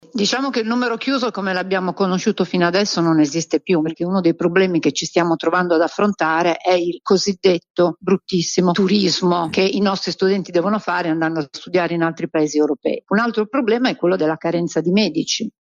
Avete appena ascoltato il commento della ministra dell’Università e della Ricerca Anna Maria Bernini alla riforma delle modalità di accesso ai corsi di laurea in Medicina.